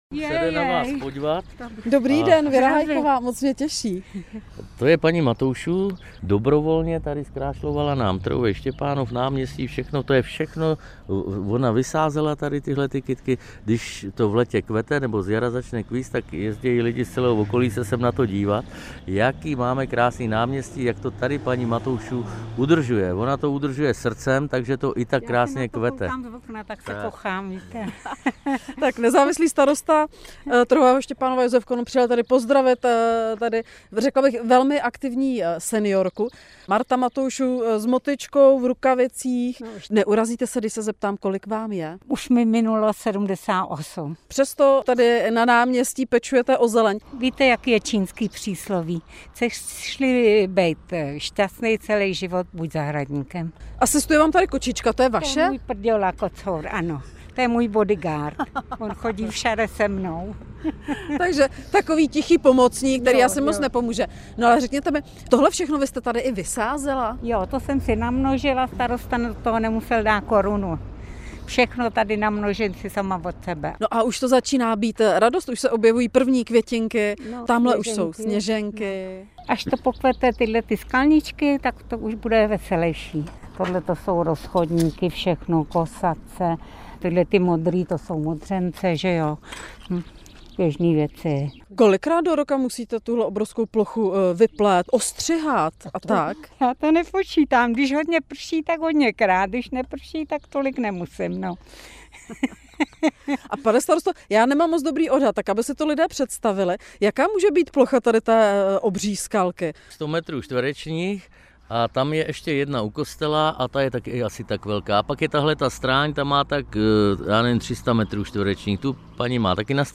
Zprávy Českého rozhlasu Střední Čechy: Vozy lanovky na pražský Petřín jdou do důchodu. Zamíří do muzejních depozitářů v Praze a Chomutově - 19.03.2025